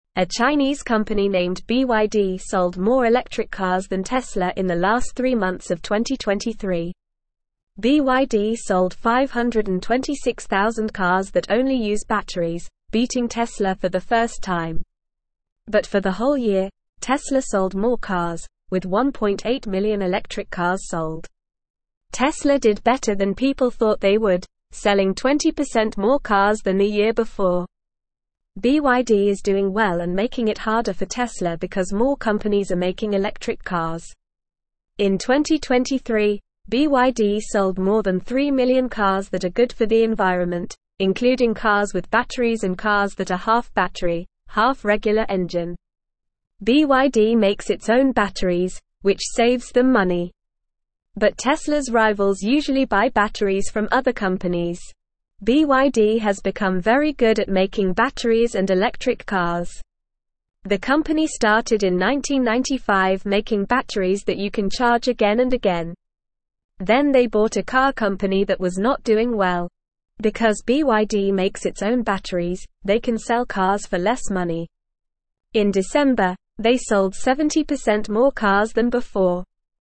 Normal
English-Newsroom-Lower-Intermediate-NORMAL-Reading-BYD-sells-more-electric-cars-than-Tesla.mp3